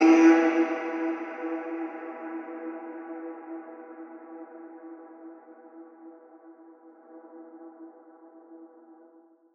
EdmDeepVox.wav